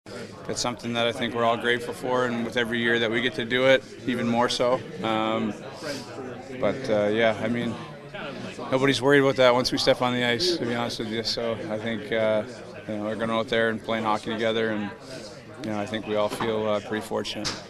Crosby, Malkin, and Letang will be starting their twentieth season together as Penguins, a pro sports record, but the captain said they won’t be thinking about that.